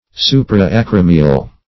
Search Result for " supra-acromial" : The Collaborative International Dictionary of English v.0.48: Supra-acromial \Su`pra-a*cro"mi*al\, a. (Anat.) Situated above the acromial process of the scapula.
supra-acromial.mp3